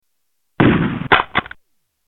Shotgun Pump